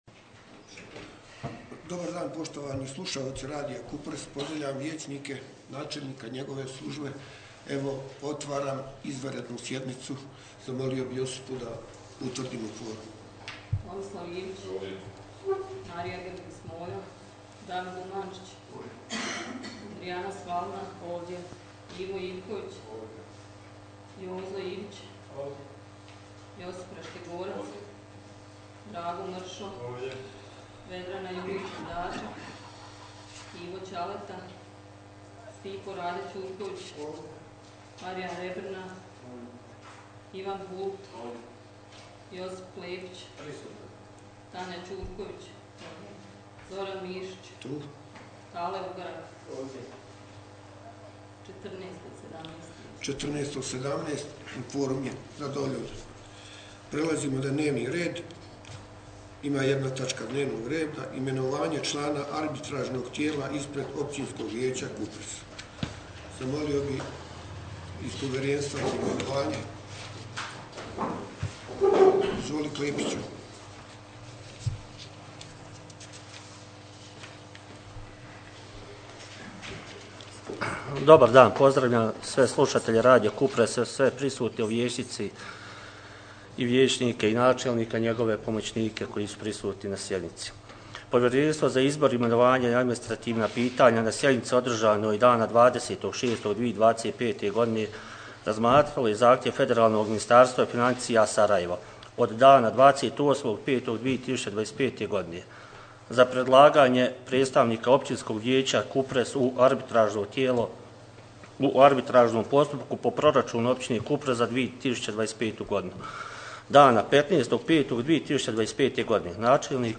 Izvanredna sjednica OV Kupres izbor arbitražnoga predstavnika vijeća
Danas je sa početkom u 14:00 h u općinskoj vijećnici zgrade općine Kupres održana izvanredna sjednica općinskog vijeća Kupres. Na sjednici je bilo nazočno 14 od 17 vijećnika općinskoga vijeća a pred vijećnicima našla se samo jedna točka dnevnoga reda.